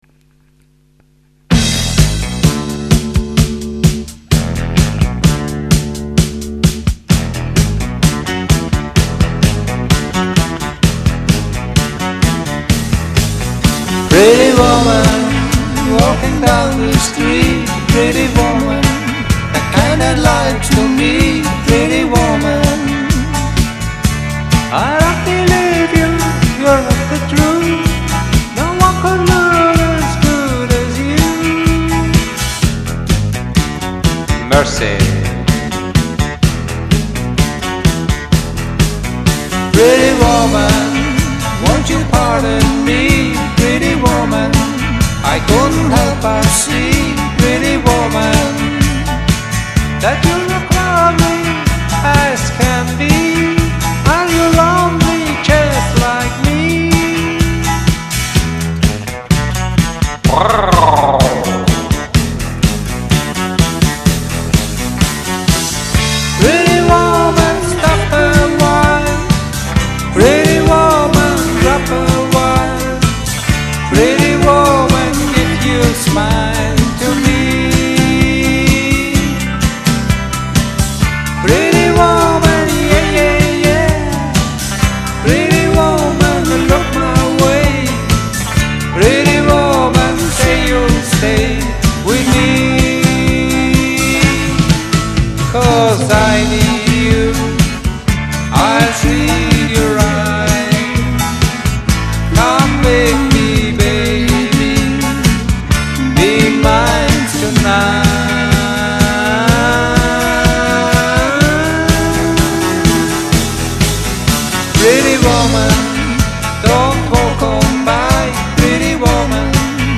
Karaoke MP3 Version